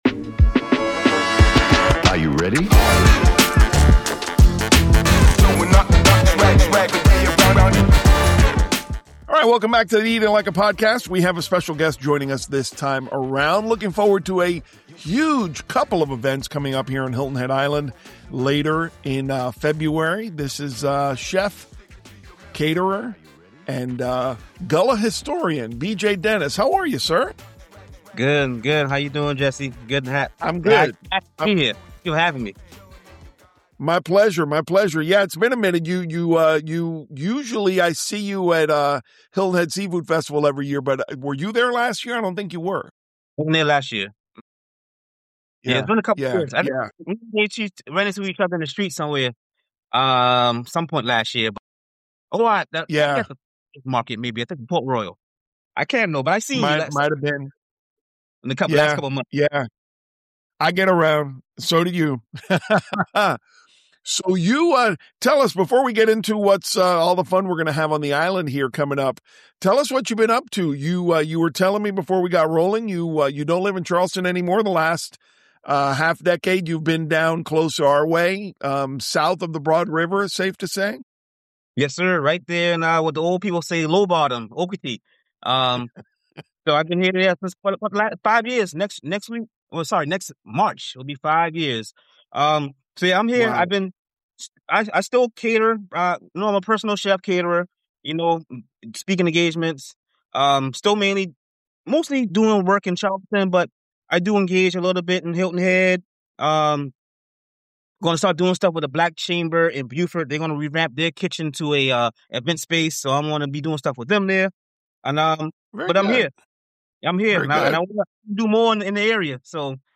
Eat It & Like It Celebrating 30 Years of Gullah Culture on Hilton Head Island: A chat with